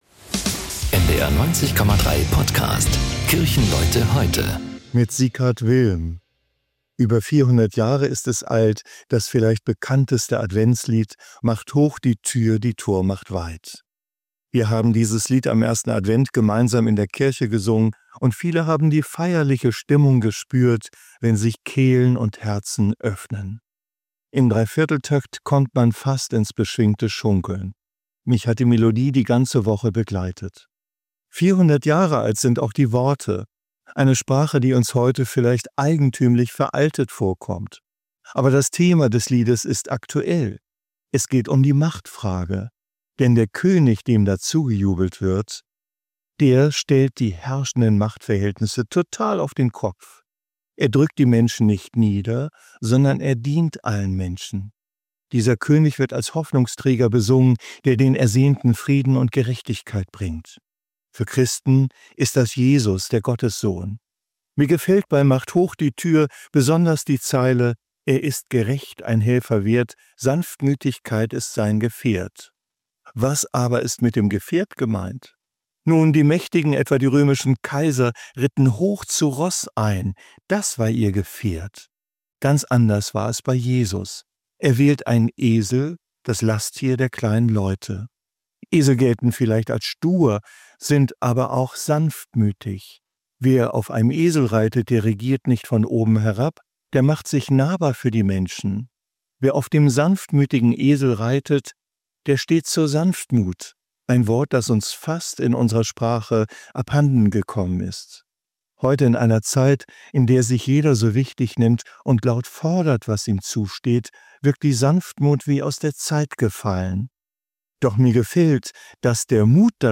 Hamburger Pastorinnen und Pastoren und andere Kirchenleute erzählen